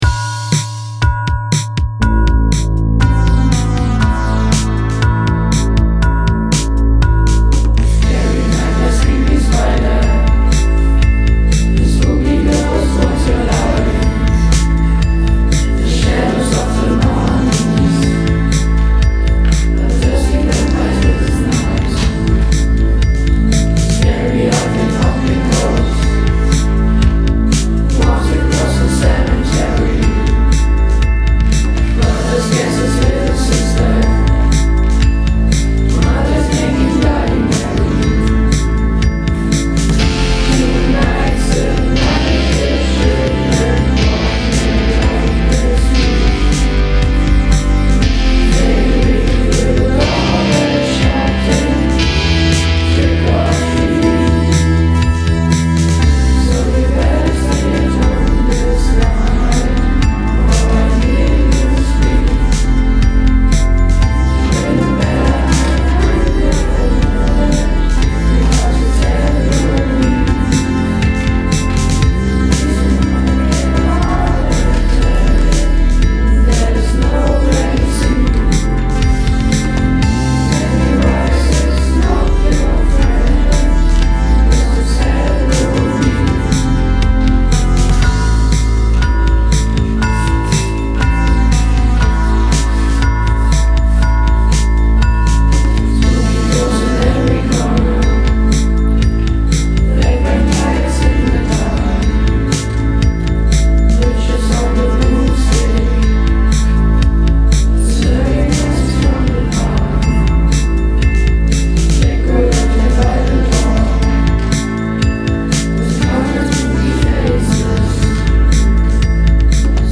Just in time for Halloween, class 8CG is releasing a super creepy song about spiders, ghosts, vampires, mummies and everything that goes with Halloween. The song was written and recorded by the students of the class themselves. If you listen carefully you can hear the spiders crawling and the vampires smacking their lips.